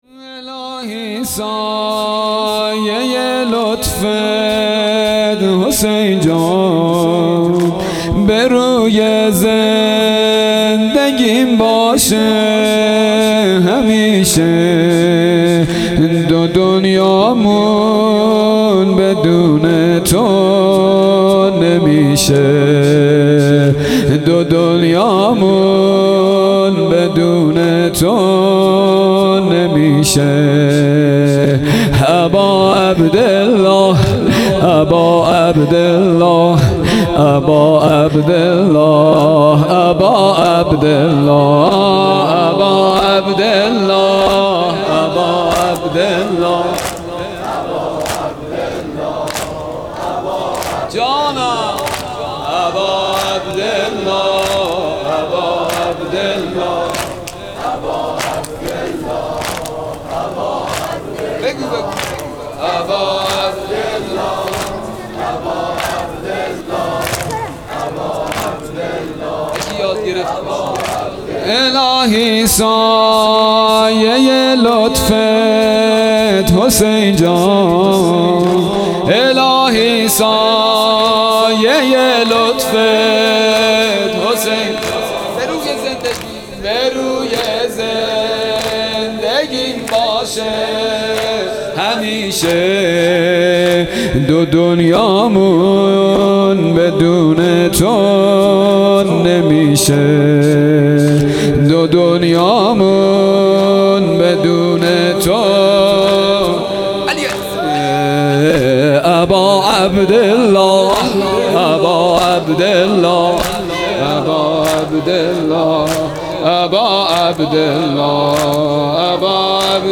2 0 شب دوم محرم الحرام 1443